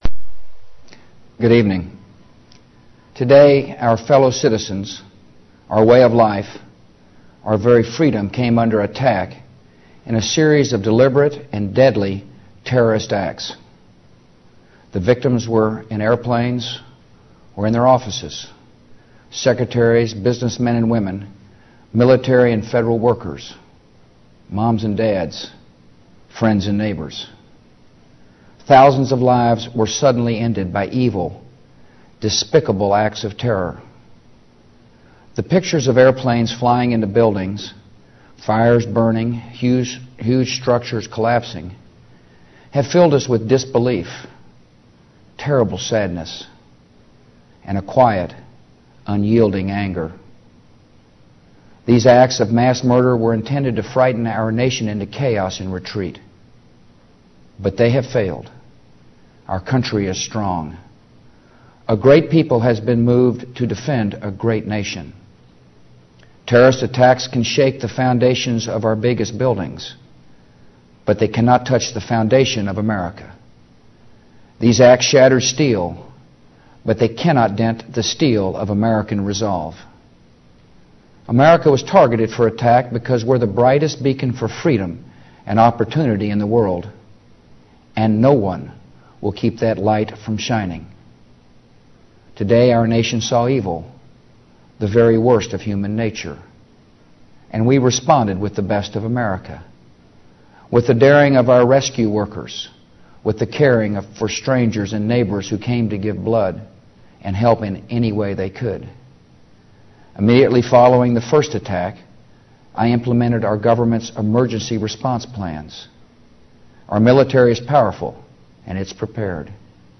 Address to the nation - 9/11/2001